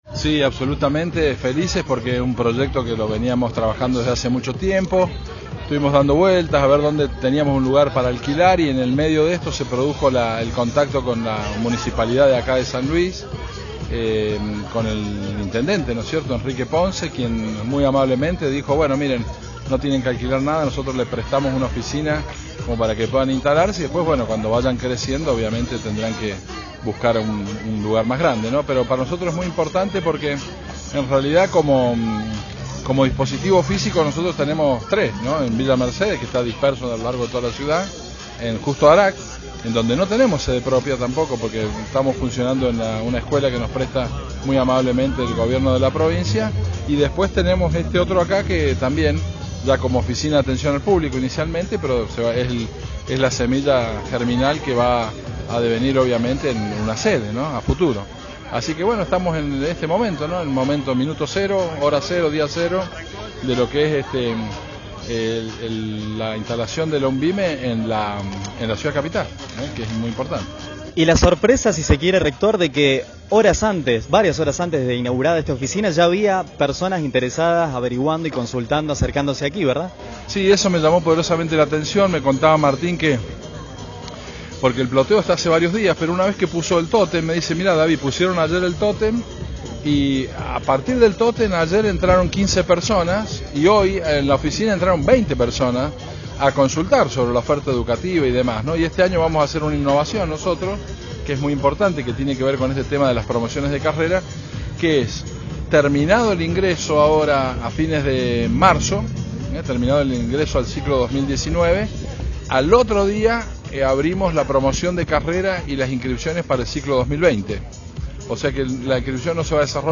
El acto presidido por el rector normalizador, Dr. David Rivarola, se concretó este jueves 21, con la presencia de autoridades nacionales, provinciales, municipales y representantes de la comunidad universitaria.
RECTOR-D.-RIVAROLAINAUG.-SAN-LUIS.mp3